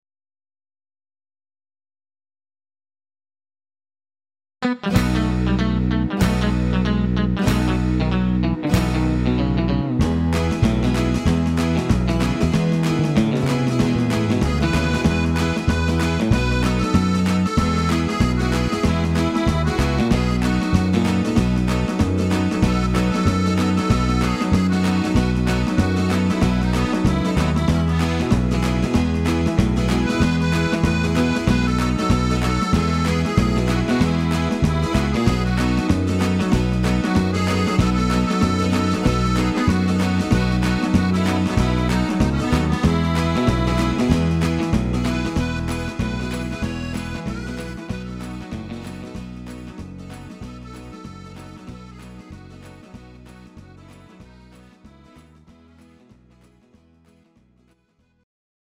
Rock n Roll & Twist